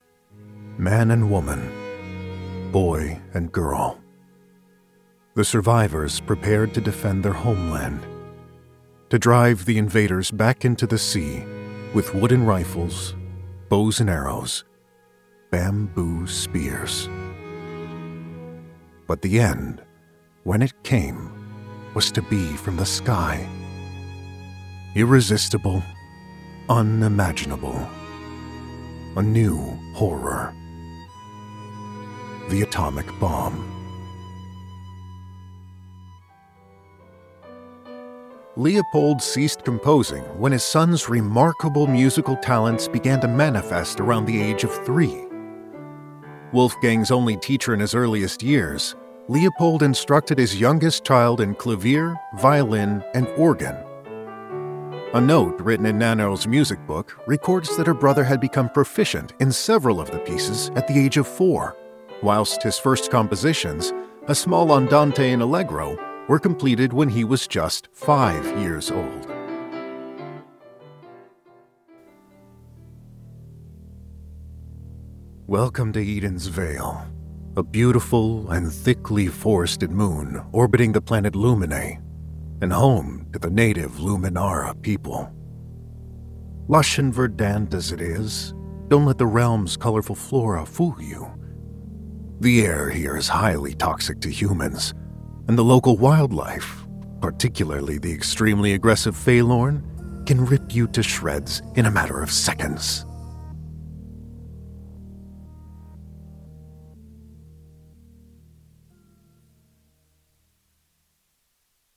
Narration
Full time Voiceover Artist for over 8 years with a pro home studio.
StudioBricks Pro Model isolation booth, Neumann U87, Neumann TLM 170R, RME Babyface Pro FS interface.
Âge moyen
BarytonBasseProfondBas